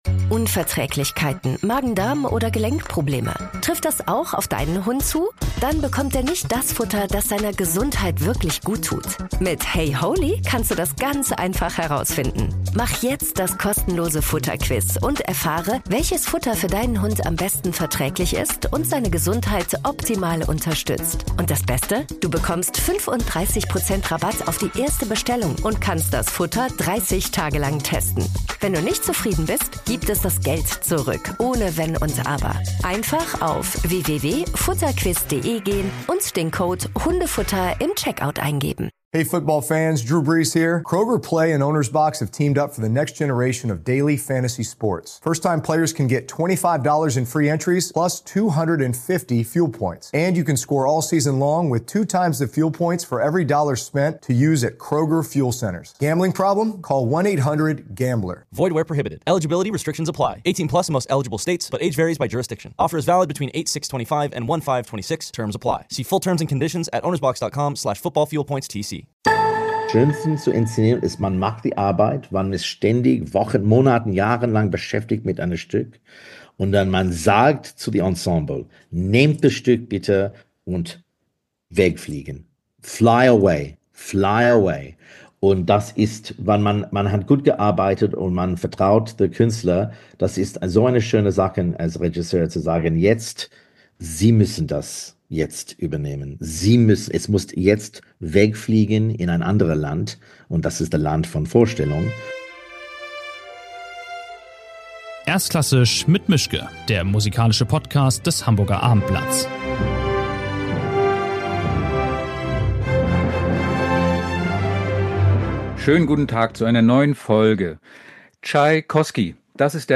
Ein Gespräch mit dem Regisseur Barrie Kosky über Musiktheater und andere Leidenschaften, Brecht und Bio-Hundefutter.